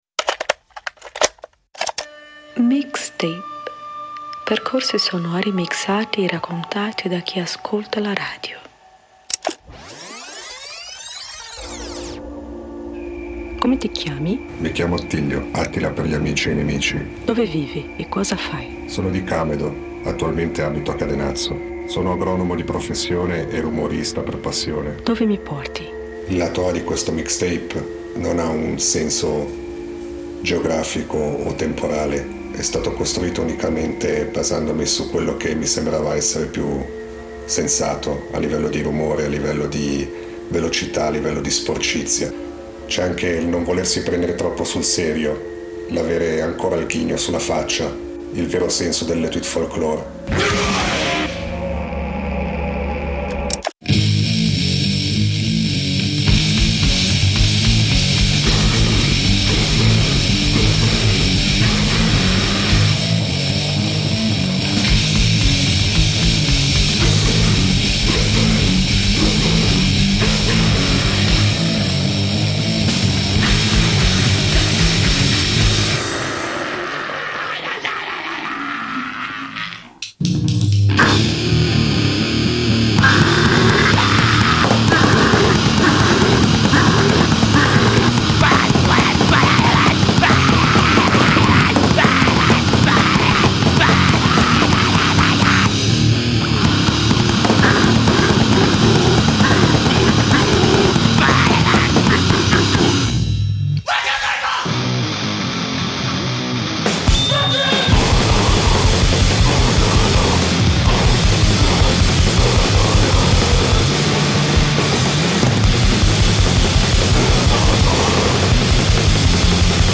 grindcore e noise
Percorsi sonori mixati e raccontati da chi ascolta la radio